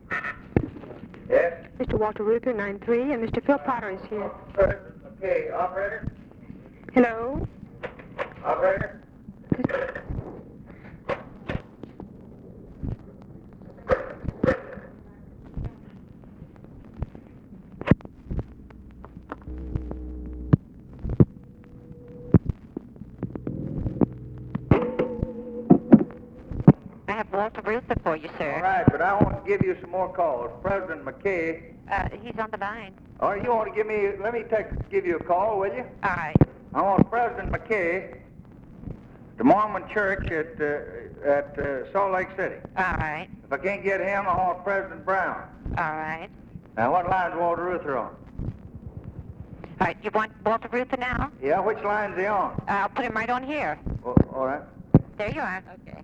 SECRETARY ANNOUNCES CALLS
Conversation with OFFICE SECRETARY, January 25, 1964
Secret White House Tapes | Lyndon B. Johnson Presidency